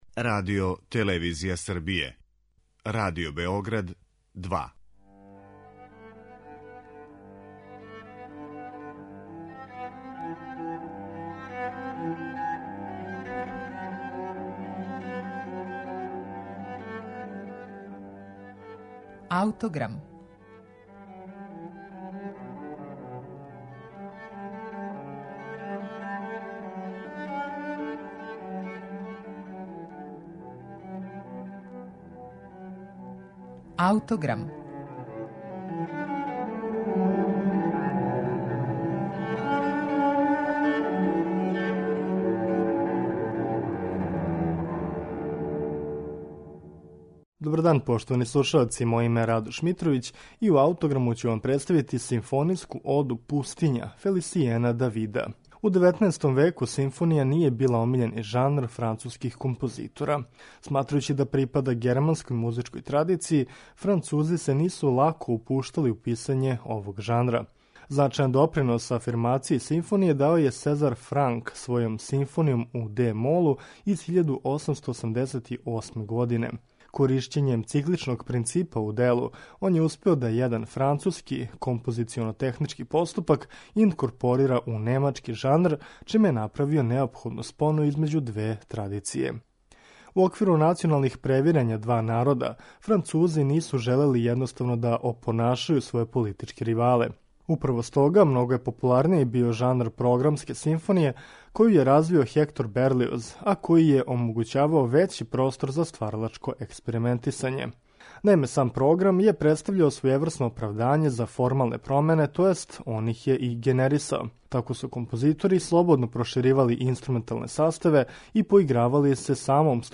Слушаћете симфонијску оду 'Пустиња', француског композитора Фелисијена Давида
Писано за велики симфонијски оркестар, мушки хор, тенора и наратора, ово дело сублимира ауторово лично искуство боравка у Египту и Алжиру.